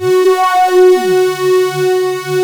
OSCAR 10 F#4.wav